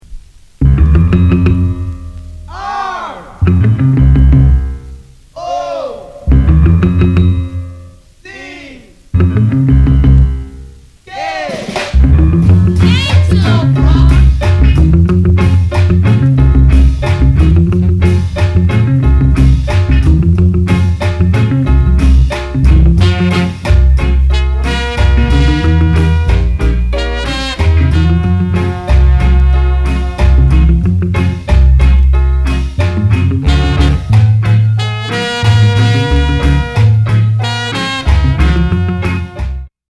をセンス良くインスト・レゲー・カバー！！